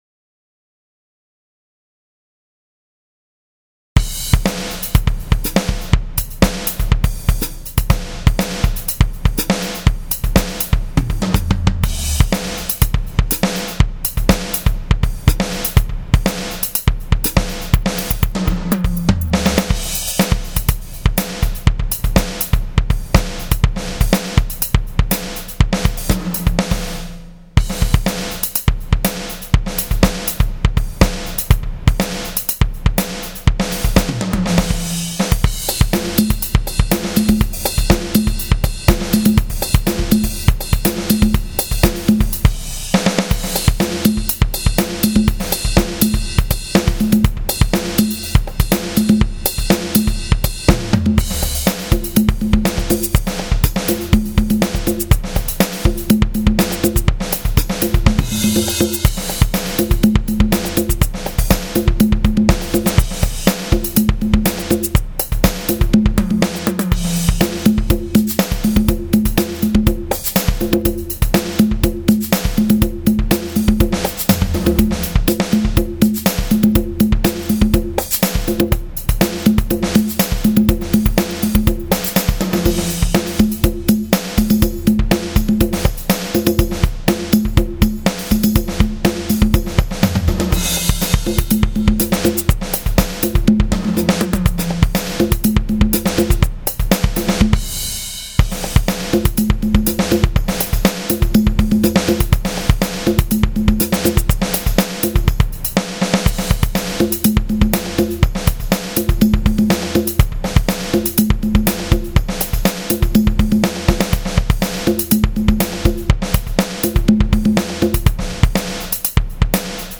Drum Grooves
Hard Funk 122.mp3